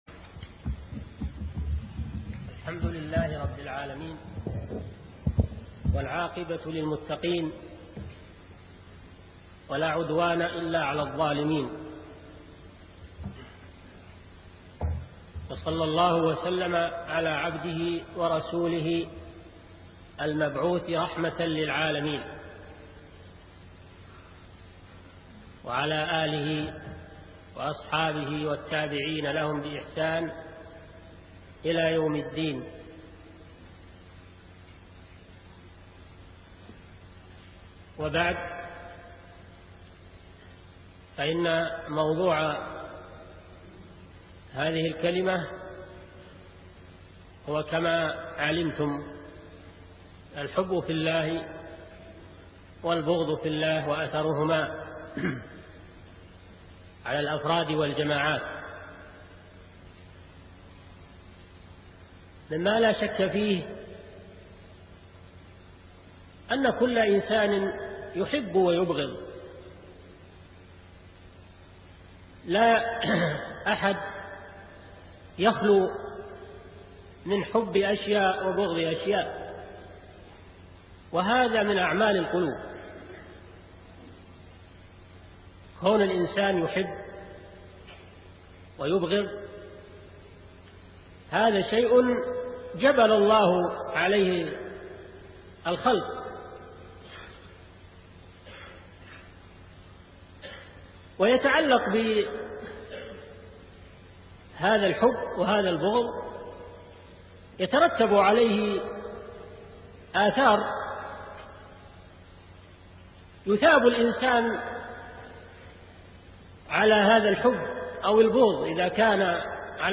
شبكة المعرفة الإسلامية | الدروس | الحب في الله والبغض في الله |صالح بن فوزان الفوزان